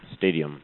stadium.mp3